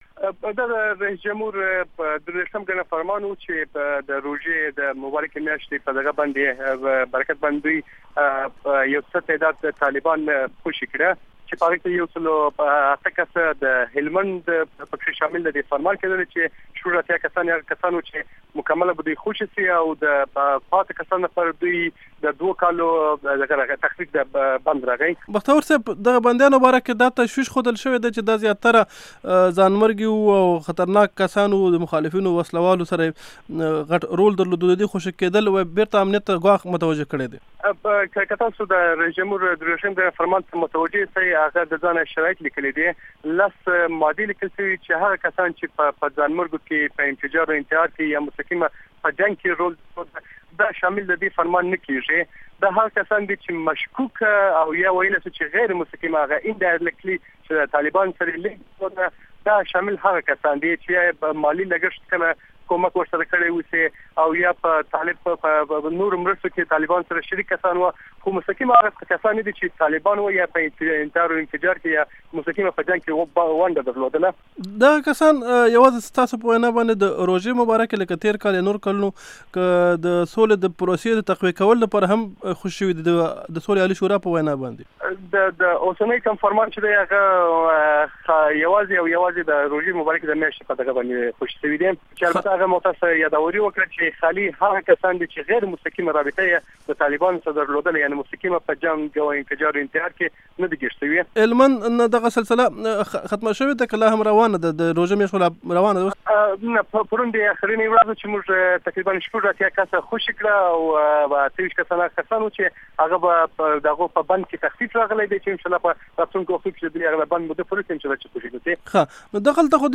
مرکې
د ښاغلي مسعود احمد بختور سره مرکه دلته اوریدی شئ